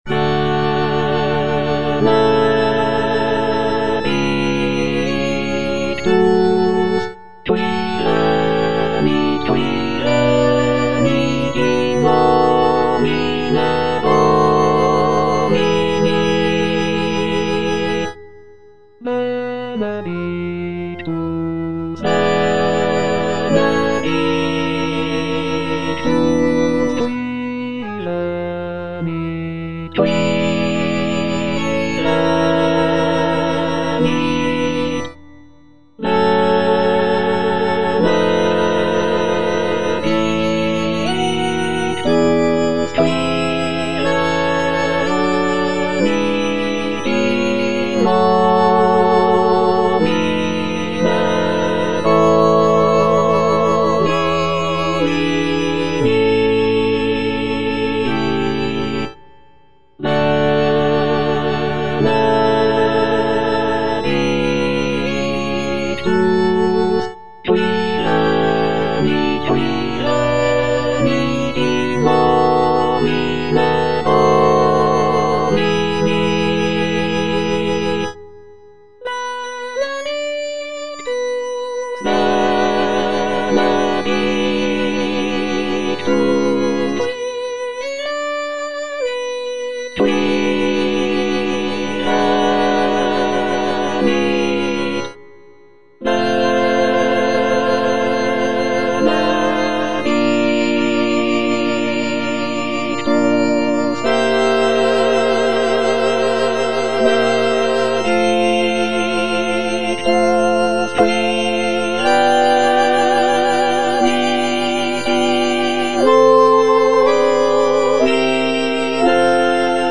F. VON SUPPÈ - MISSA PRO DEFUNCTIS/REQUIEM Benedictus - Bass (Emphasised voice and other voices) Ads stop: auto-stop